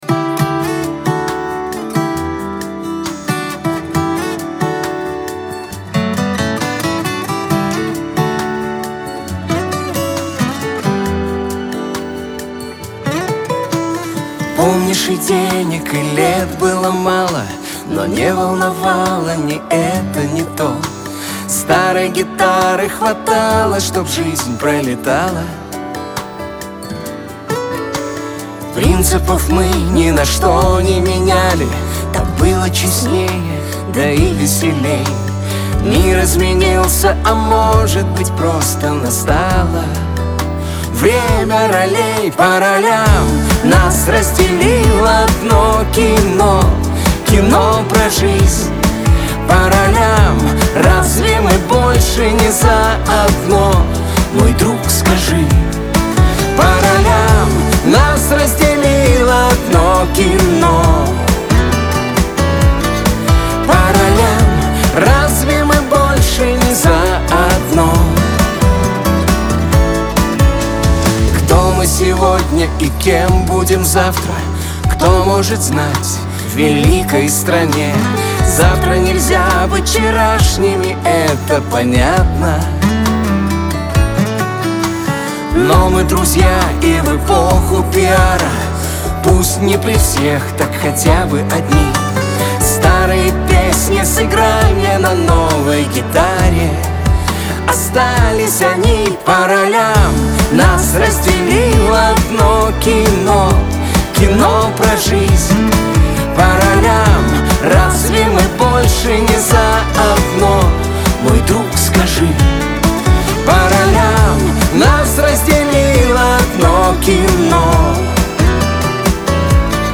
pop , диско
эстрада , дуэт